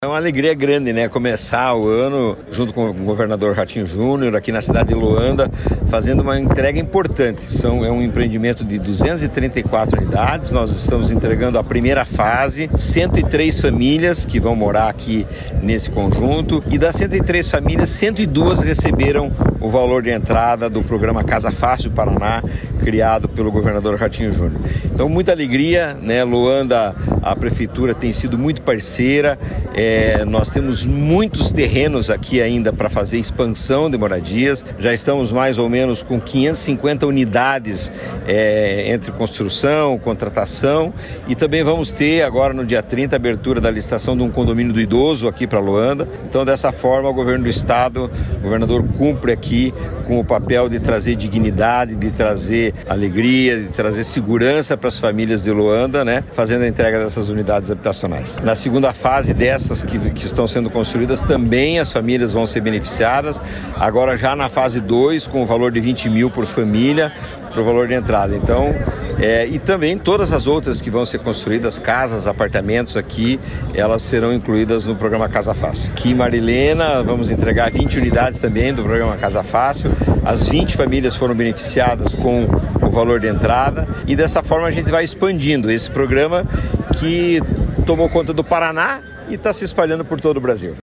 Sonora do presidente da Cohapar, Jorge Lange, sobre a entrega de um condomínio em Loanda com 102 casas subsidiadas pelo Estado